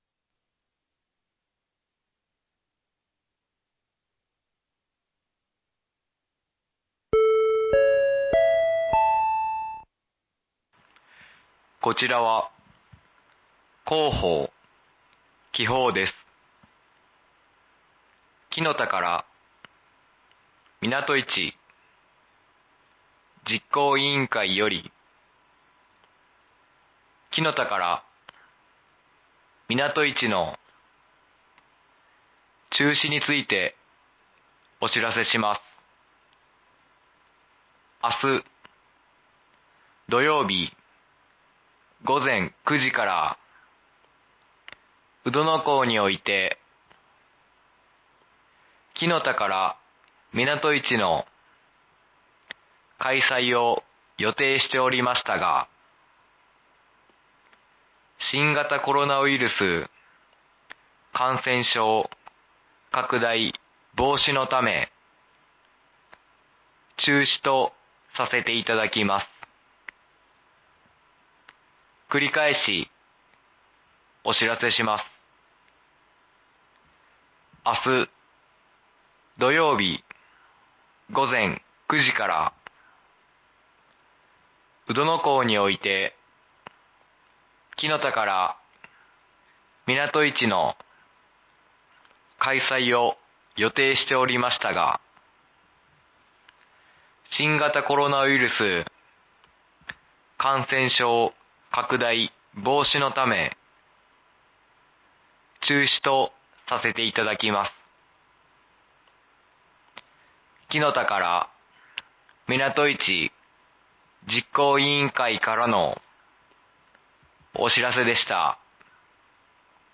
紀宝町防災無線情報
放送音声